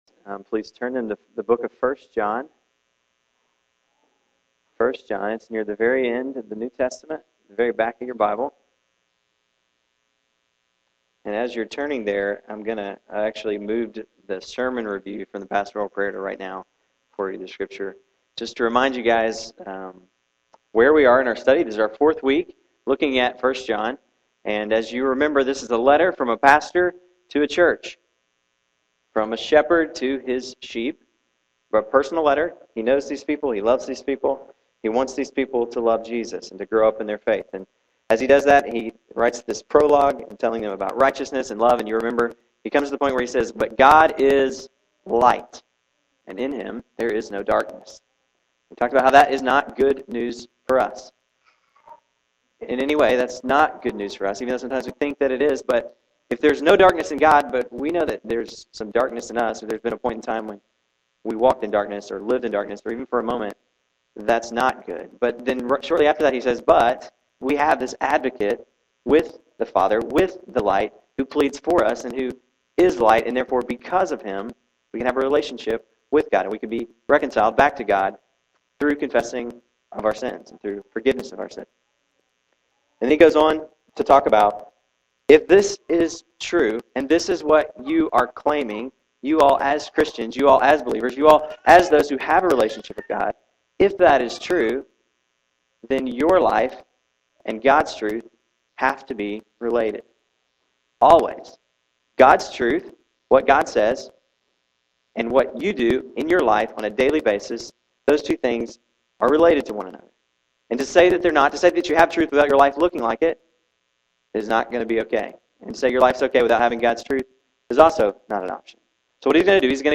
March 21, 2010 AM Worship | Vine Street Baptist Church
With the sun shining bright, members and guests gathered in the Worship Center for a blessed time of being together, singing praises and learning about God.
The congregation sang “His Name is Wonderful,” then the offering was collected.